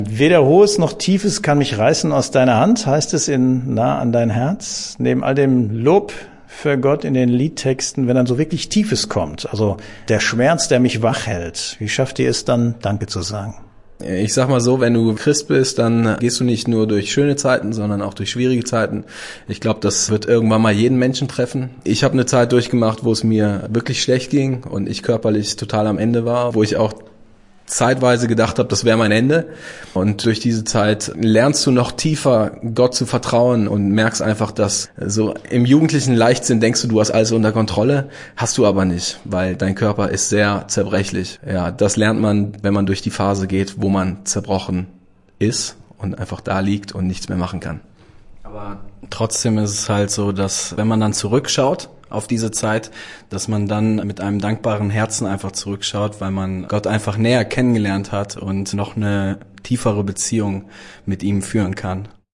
AREF-Sendebeitrag: KOENIGE & PRIESTER - Worship - Rock - Pop und Statements einer außergewöhnlichen Band im Interview